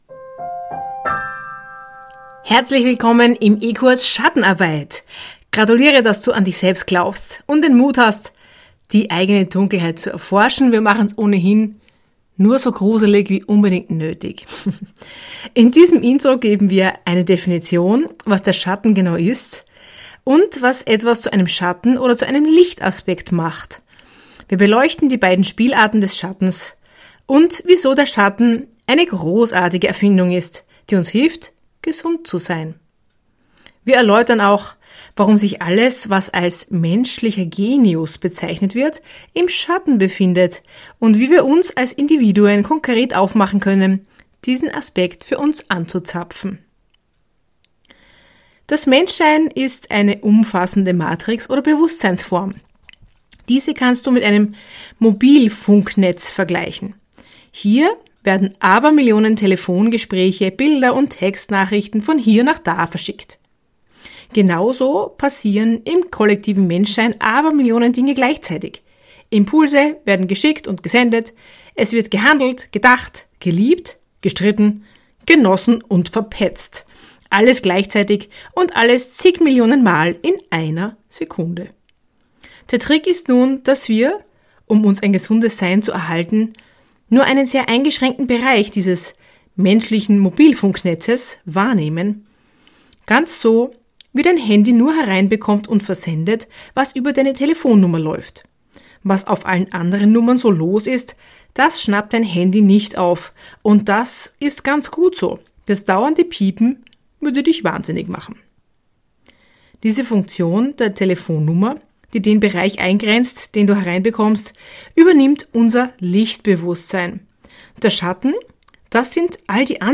Format: Hörbuch und fachspezifisches eBook